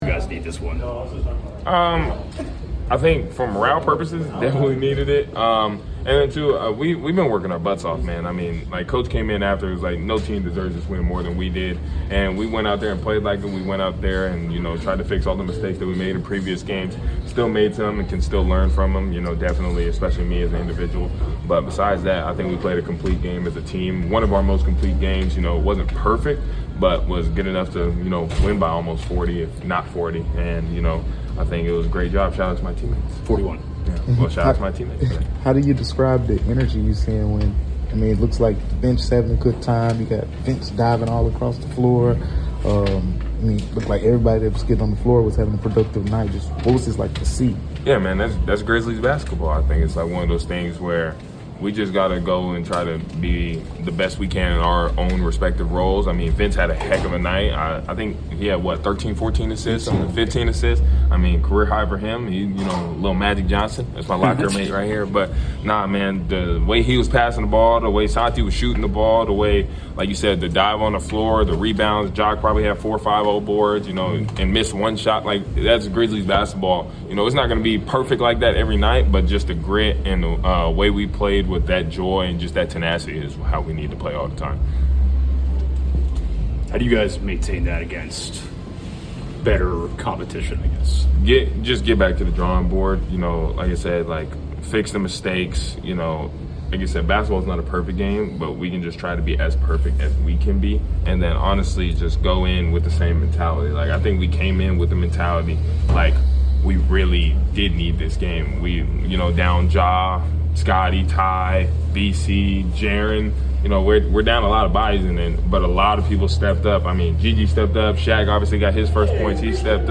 Memphis Grizzlies Forward Cedric Coward Postgame Interview after defeating the Sacramento Kings at FedExForum.